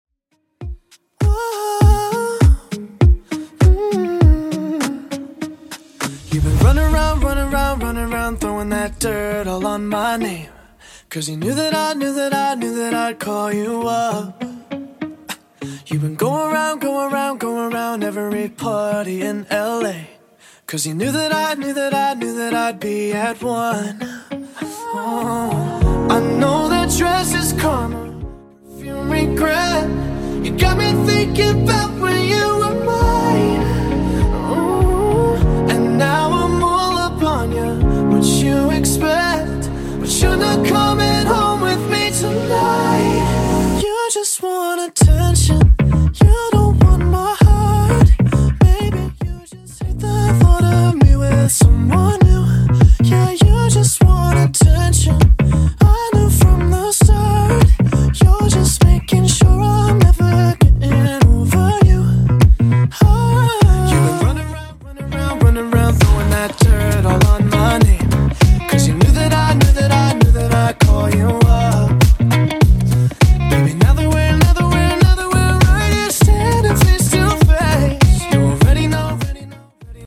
Genre: 80's Version: Clean BPM: 193